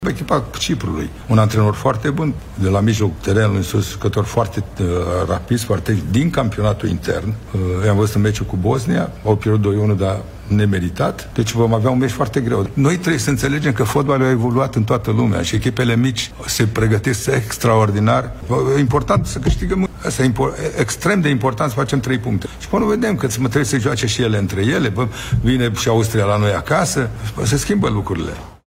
Și meciul cu Cipru va fi greu, dar mai sunt șanse de calificare –  spune selecționerul Mircea Lucescu.